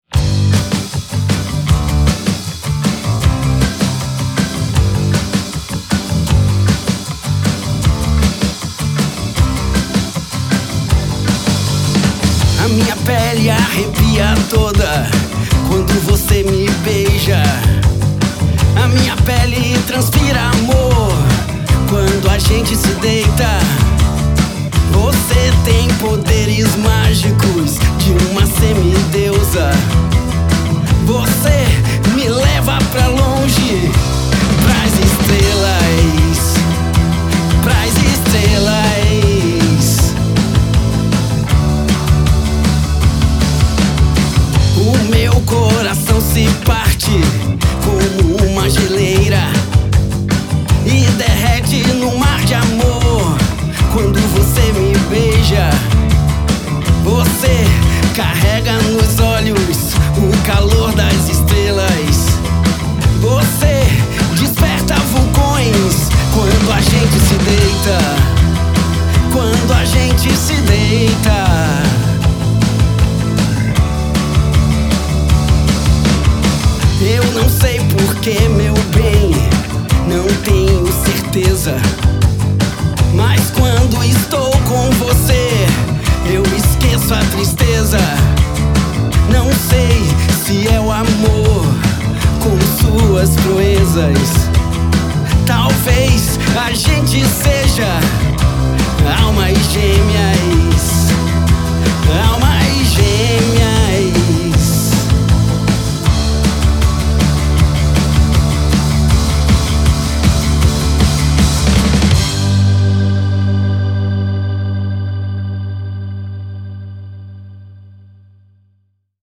todas guiadas pelo violão de cordas de aço